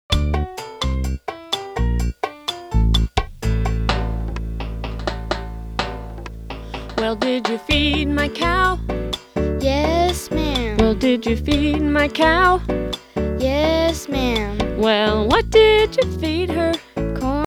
Traditional Song Lyrics and Sound Clip